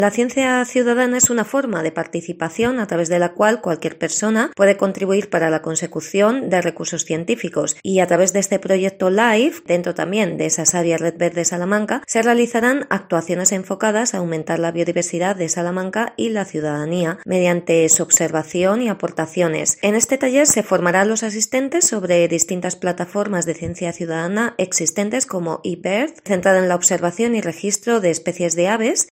La concejala Myriam Rodríguez informa que es otra iniciativa del proyecto Life Vía de la Plata